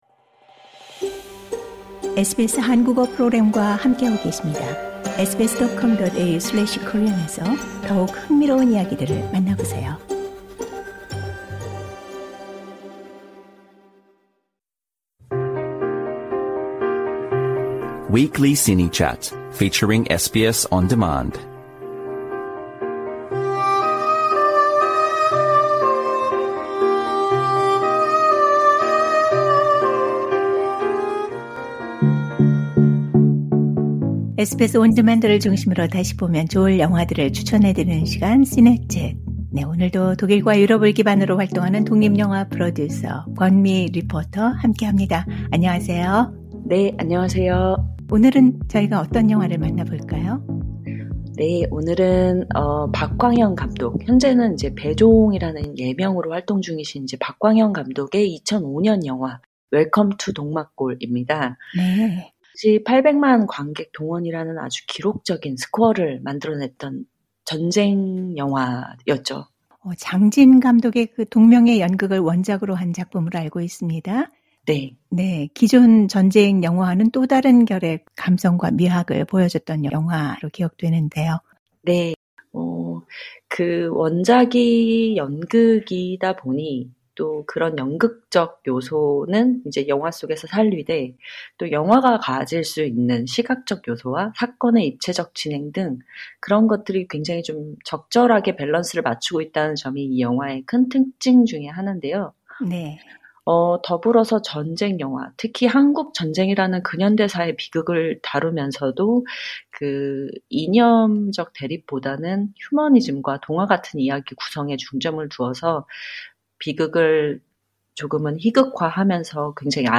Trailer Audio Clip